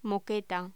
Locución: Moqueta
voz